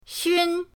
xun1.mp3